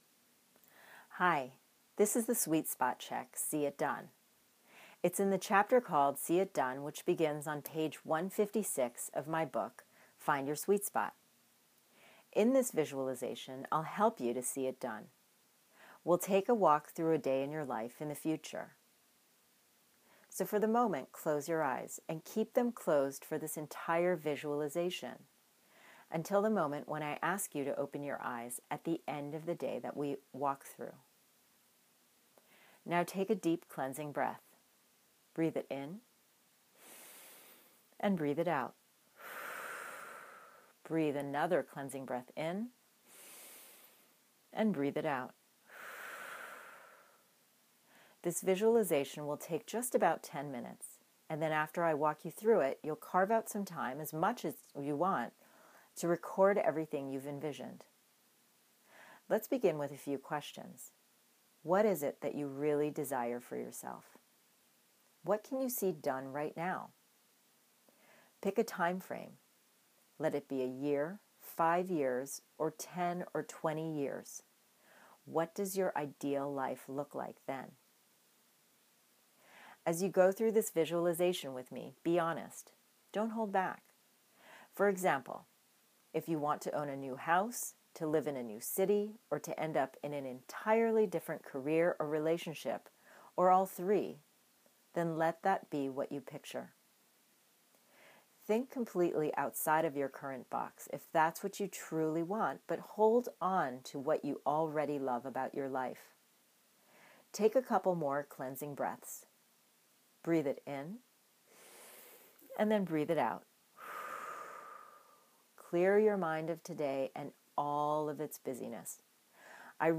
These downloads are the visualization exercises from my book that I've designed to help you to find your sweet spot. Instead of reading through these, you can close your eyes and instead be guided by my voice.